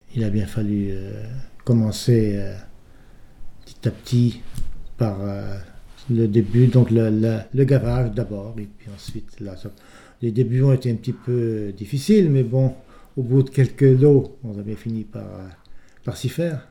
Il provient de Saint-Gervais.
Témoignage ( mémoire, activité,... )